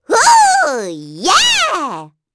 Pansirone-Vox_Happy4_kr.wav